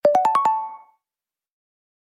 • Категория: Рингтон на смс